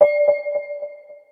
menuclick.mp3